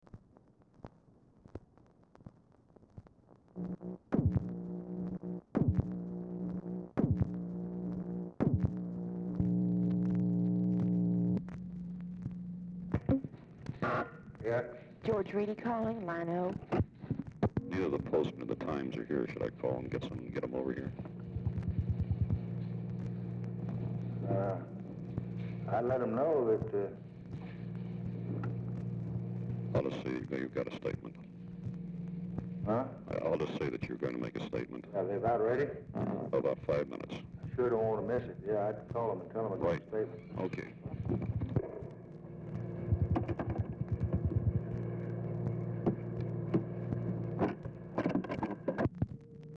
Telephone conversation
OFFICE CONVERSATION PRECEDES CALL
Format Dictation belt